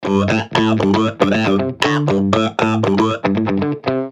• Качество: 320, Stereo
гитара
забавные
смешные
Бессмысленная, но весёлая болтовня